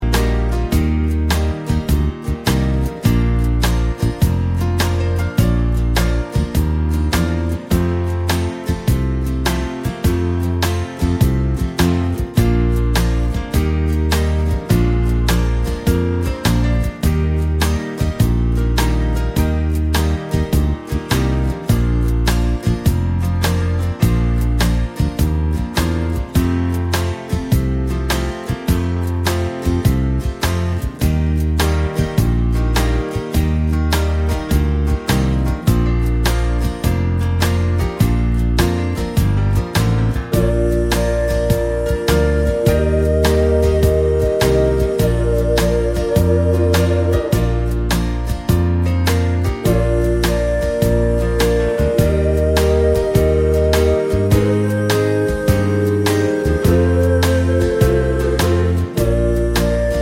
no Backing Vocals Soft Rock 4:17 Buy £1.50